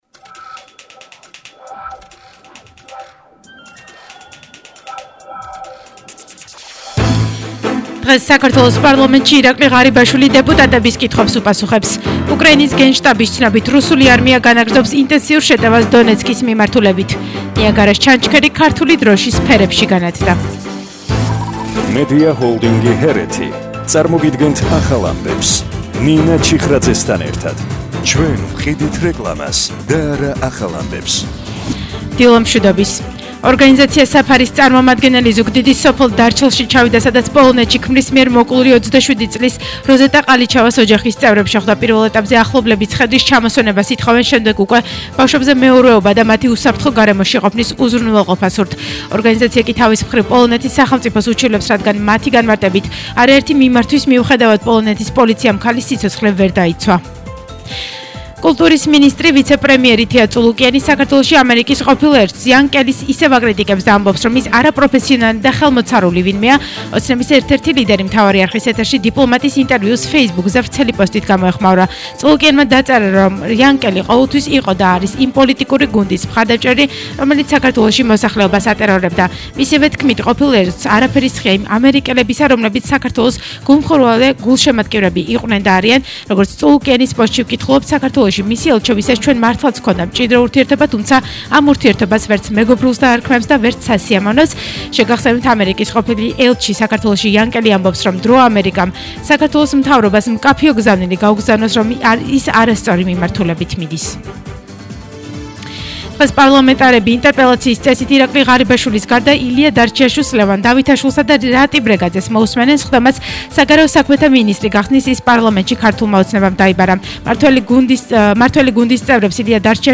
ახალი ამბები 11:00 საათზე – 27/05/22